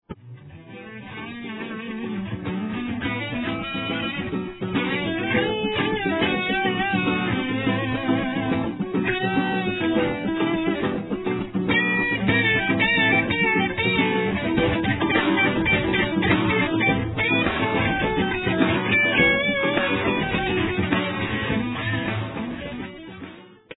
The way it will work, is I'll provide a back-up track of a rhythm-guitar and drum.
It's a VERY simple loop, with nothing really happening with the drums and rhythm, just so that it stays out of the way so you can concentrate on your guitar playing.
I just pulled a quick improvisation. i don't know how you did the high quality mp3 link, but here's my incredibly low quality and short clip.
Took a lot out of the tone, but oh well.